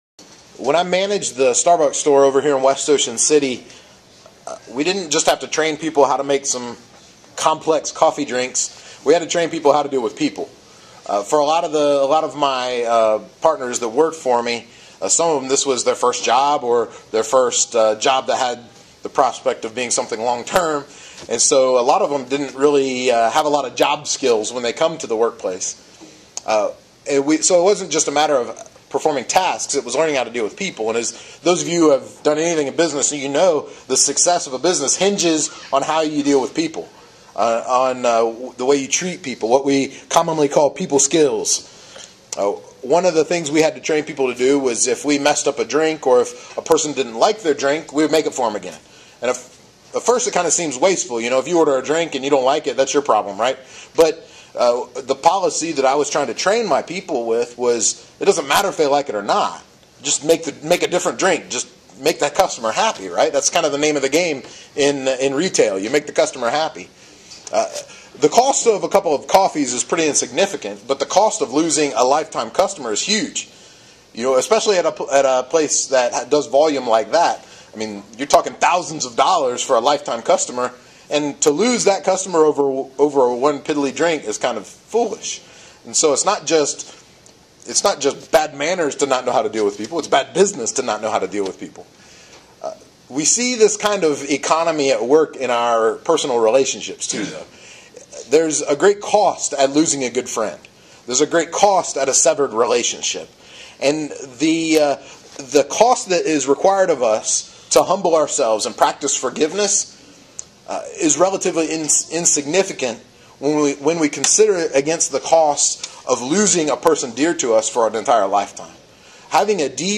The Parable of the Unforgiving Servant – Shore Community Church